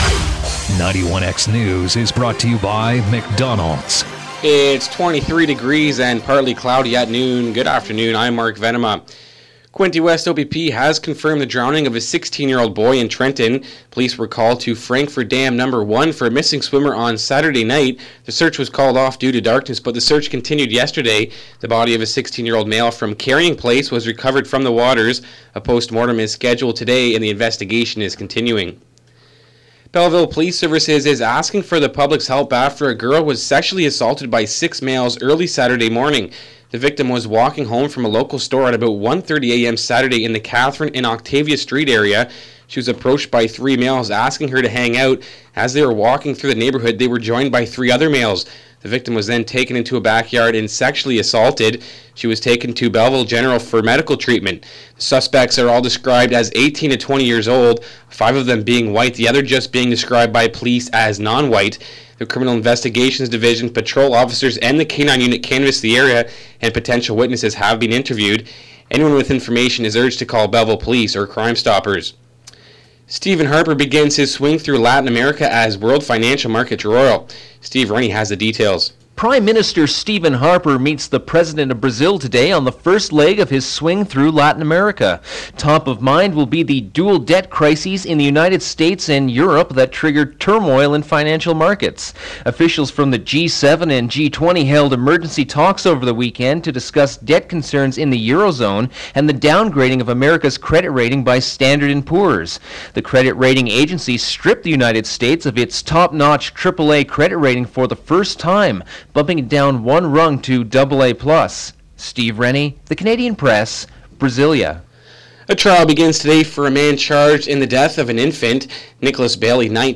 91x News, August 8, 2011, 12pm